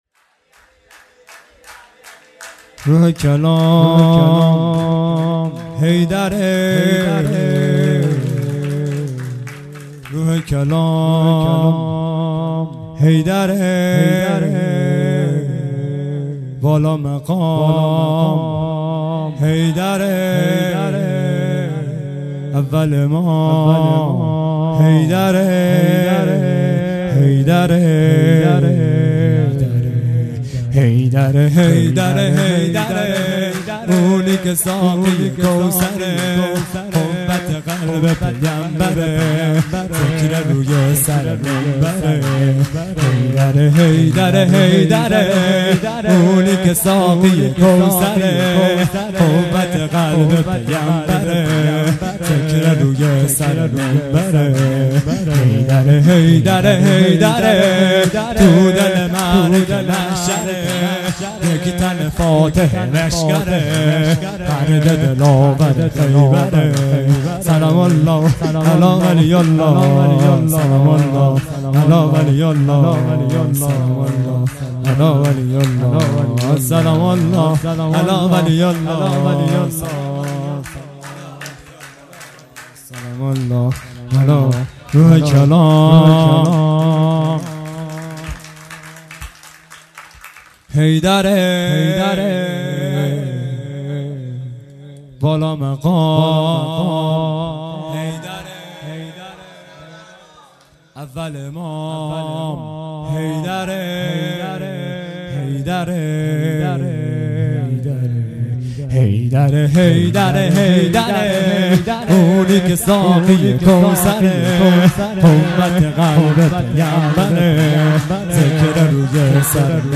0 0 سرود
جلسه هفتگی هیئت به مناسبت ولادت حضرت معصومه(س)